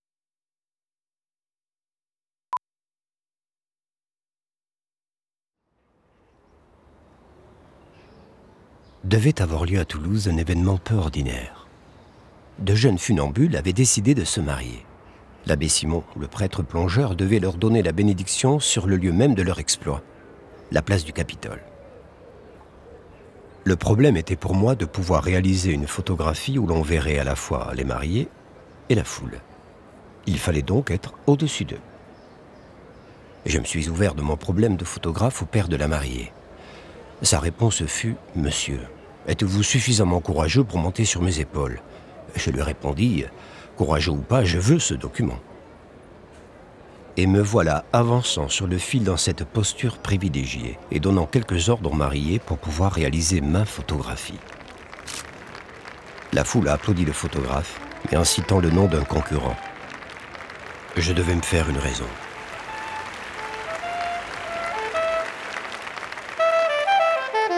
Voix narratives et jouées – Documentaires